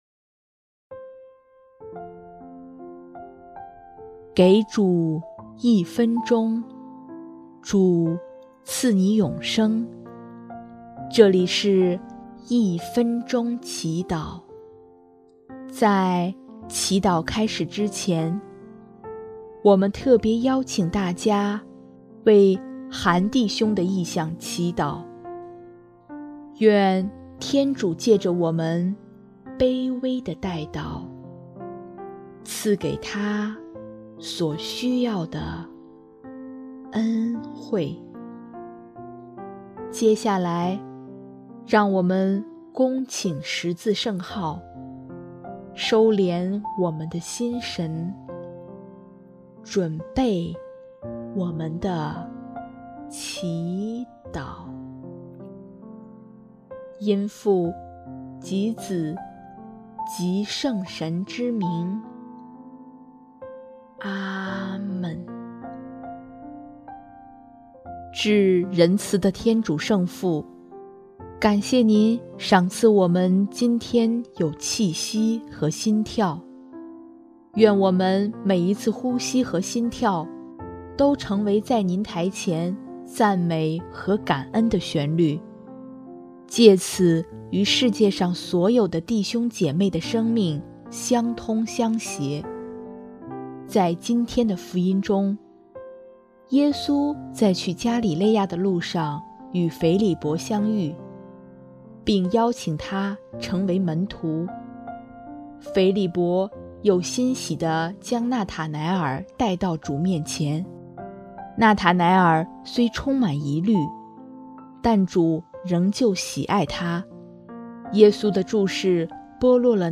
音乐： 第三届华语圣歌大赛参赛歌曲